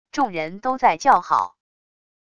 众人都在叫好wav音频